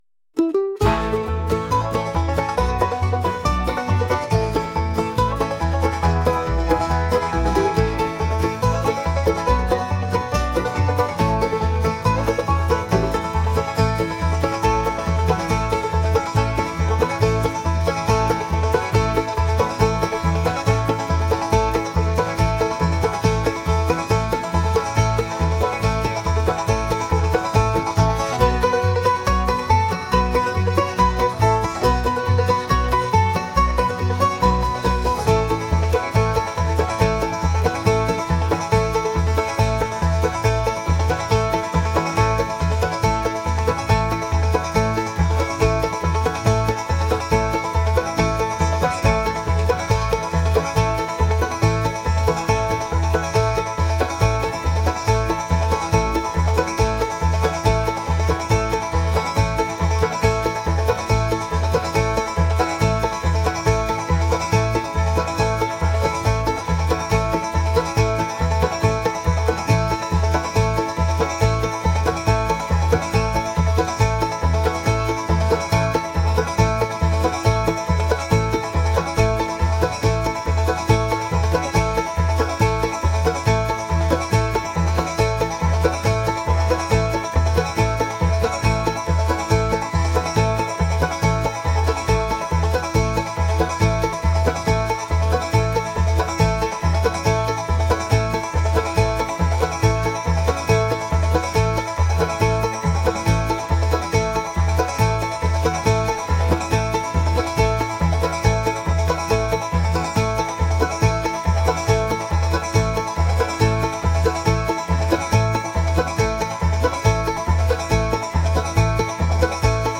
lively